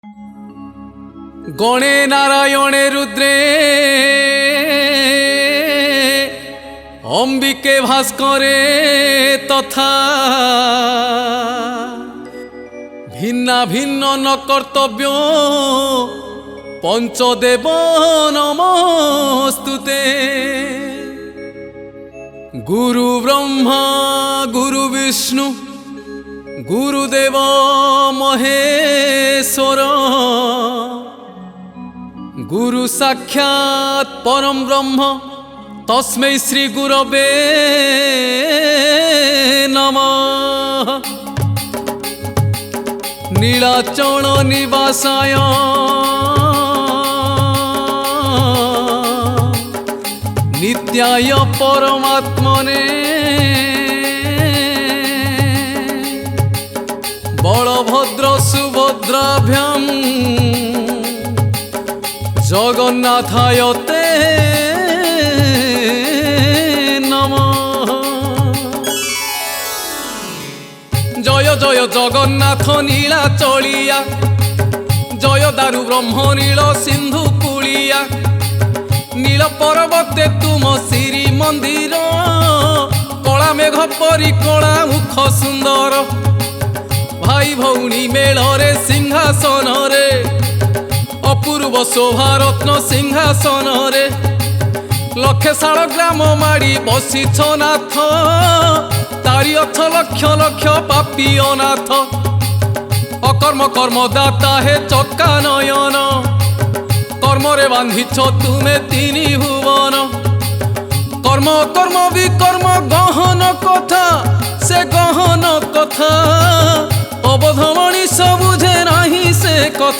Odia Bhajan